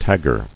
[tag·ger]